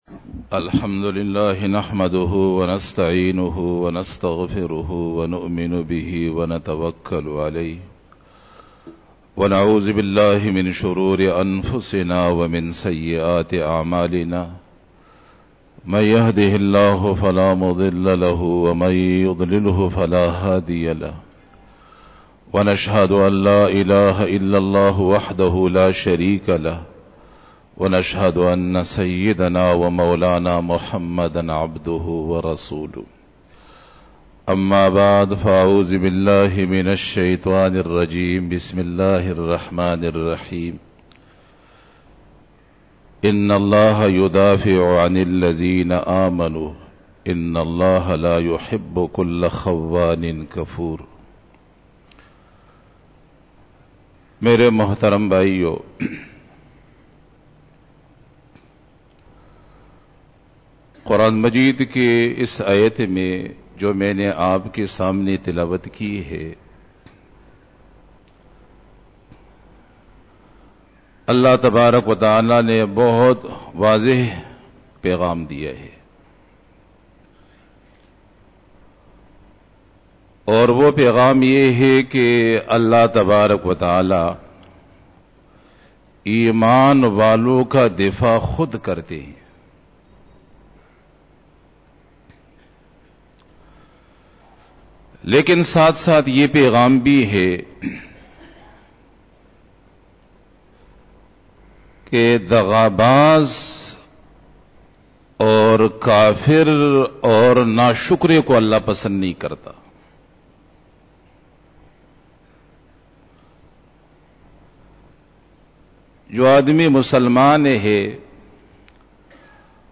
02 BAYAN E JUMA TUL MUBARAK (12 JANUARY 2018) (25 Rabi us Sani 1439H)
Khitab-e-Jummah 2018